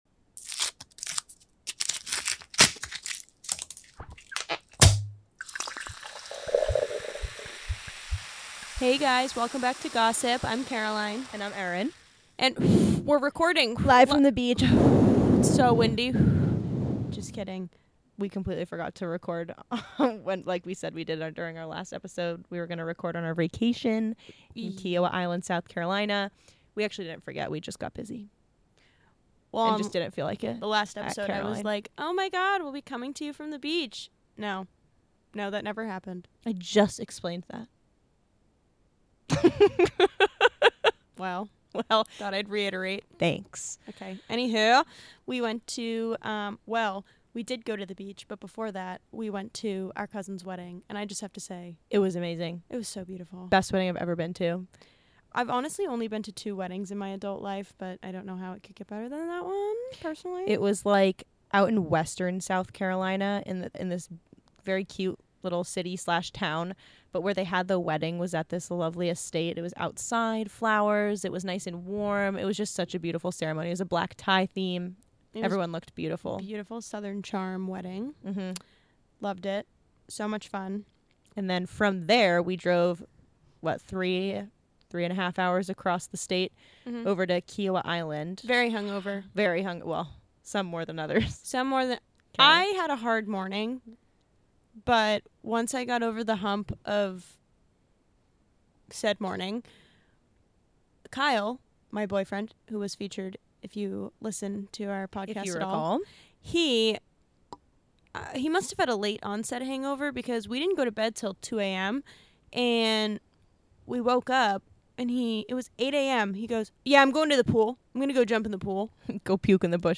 A podcast where two sisters talk about anything and everything from current events to pop culture to true crime.